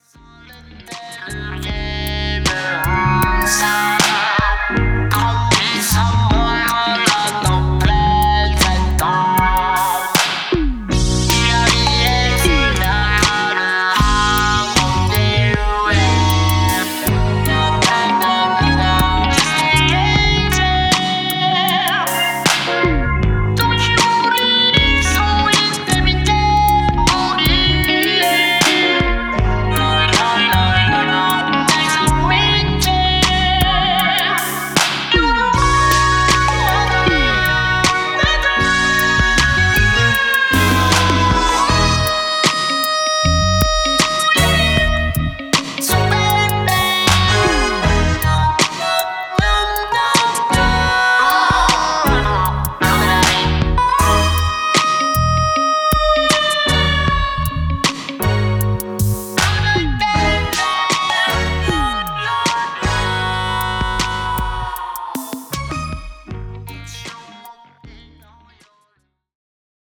メロウなスロウジャム
ジャンル(スタイル) JAPANESE / SOUL / FUNK / CLUB